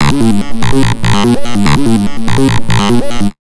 AMB147SYN-L.wav